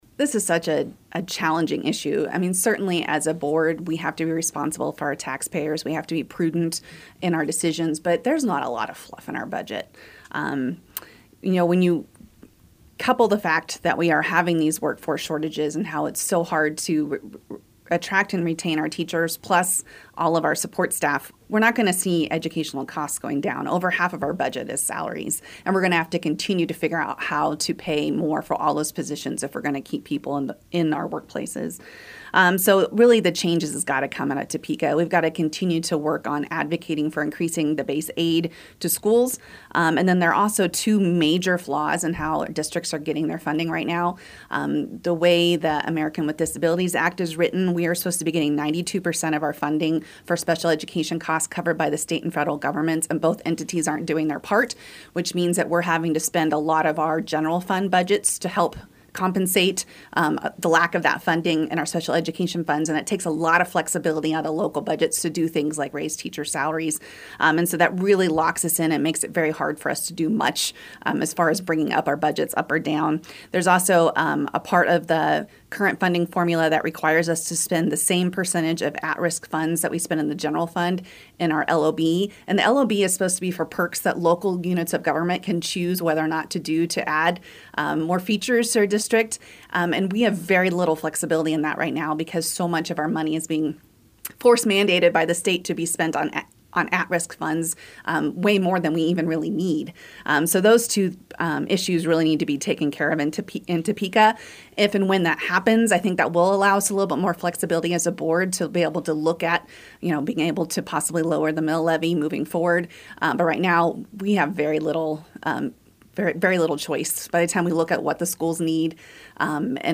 News Radio KMAN has invited candidates seeking a seat on the Manhattan City Commission and Manhattan-Ogden USD 383 school board to be interviewed ahead of Election Day.